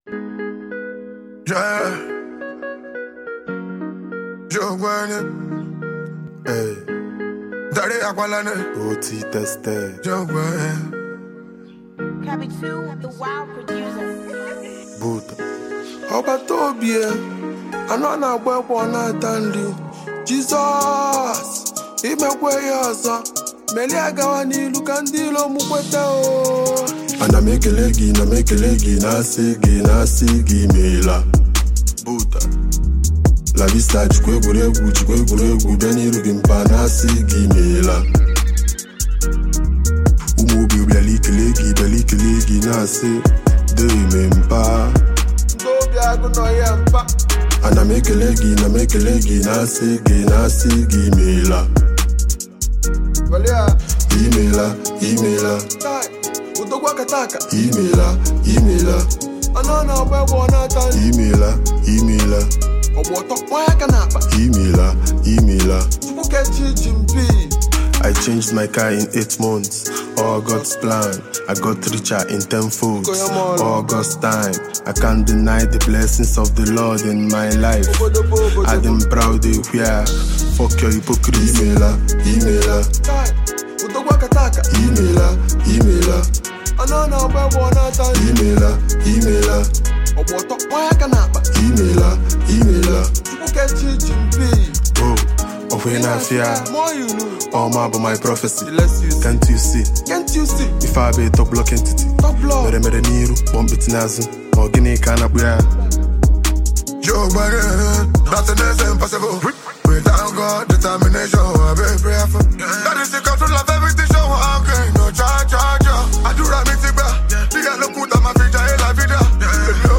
a phenomenally gifted indigenous hip-hop artist from Nigeria